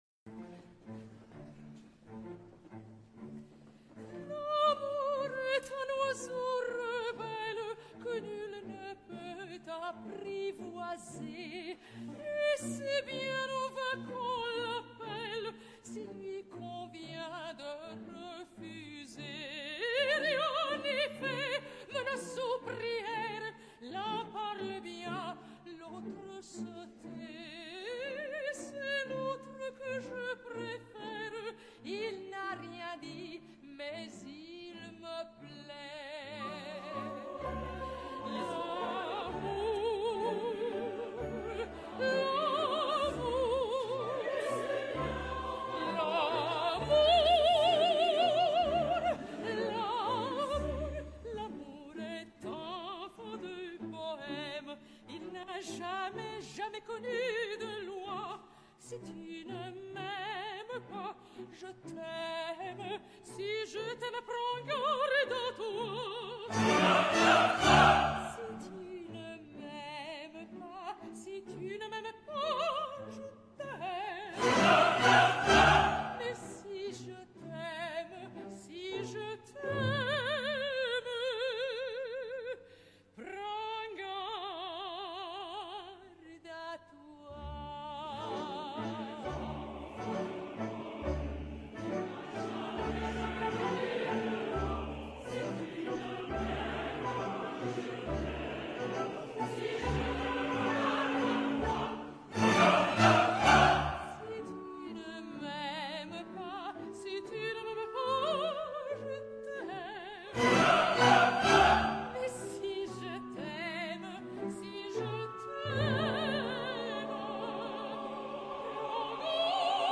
MEZZO